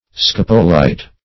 Scapolite \Scap"o*lite\ (sk[a^]p"[-o]*l[imac]t), n. [Gr.